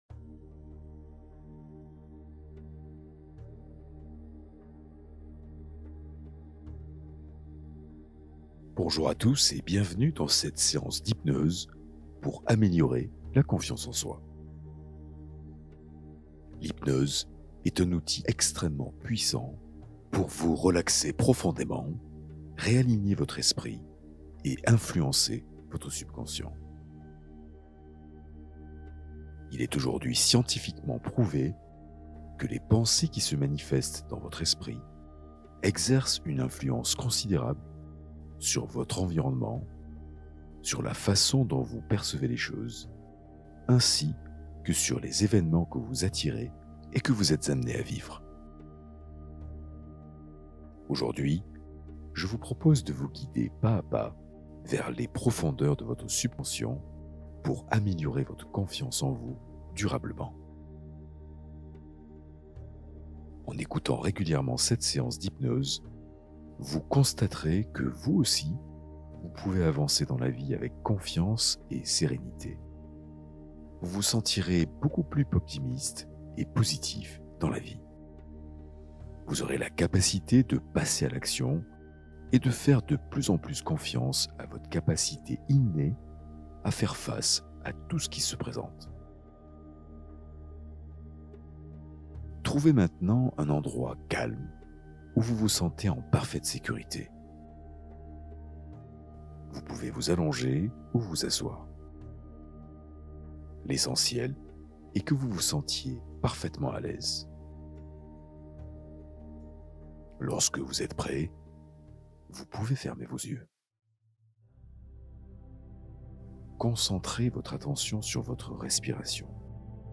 Méditation guidée pour apaiser ton corps et ton esprit dans la gratitude